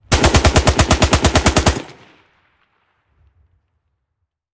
Точный короткий выстрел из автомата в тоннеле